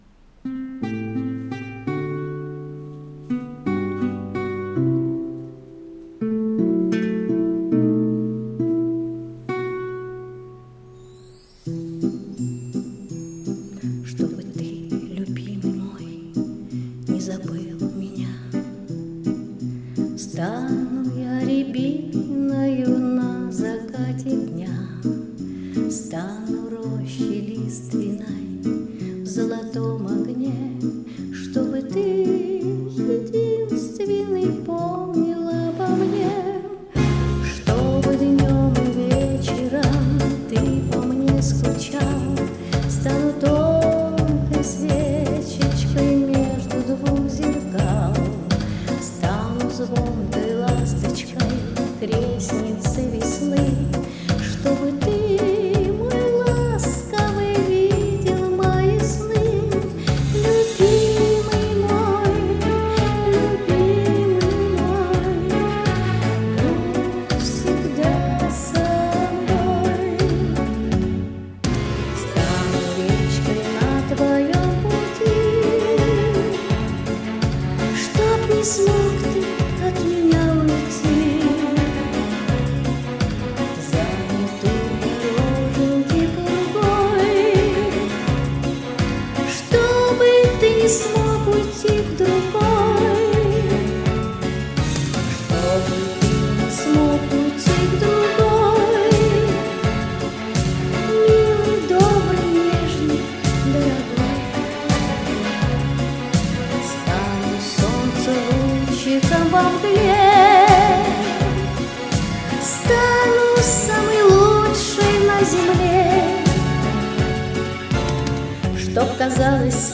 Два великолепных, красивых голоса .....очень долго решал .